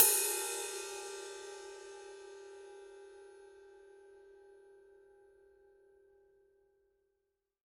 Type Ride
20_ride_body.mp3